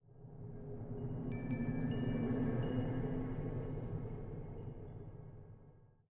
scpcb-godot/SFX/Ambient/Forest/ambient8.ogg at e4012f8b7335c067e70d16efd9e1b39f61021ea4